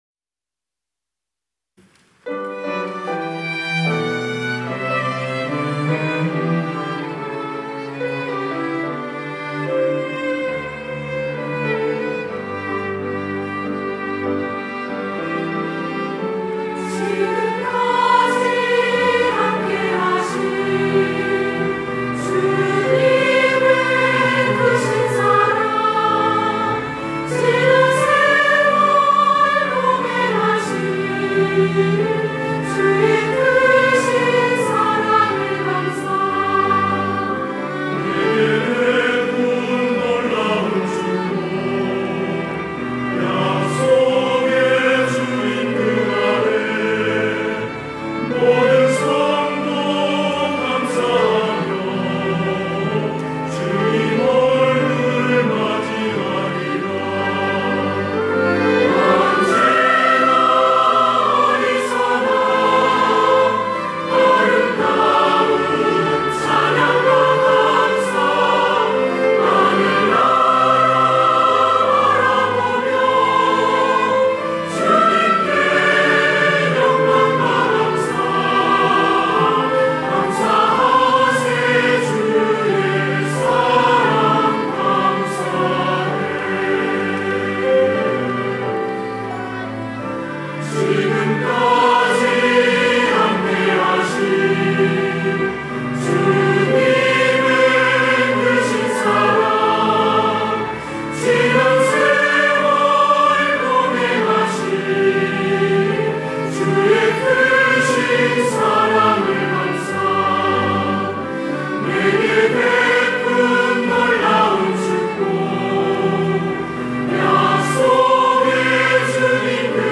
할렐루야(주일2부) - 주의 모든 일에 감사드리며
찬양대